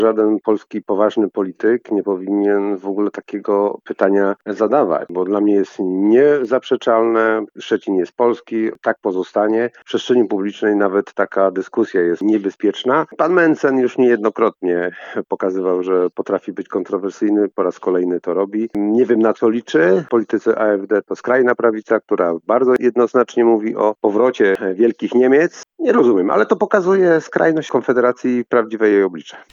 W tej sprawie dla Twojego Radia wypowiedział się poseł Polskiego Stronnictwa Ludowego Jarosław Rzepa, który nie kryje oburzenia: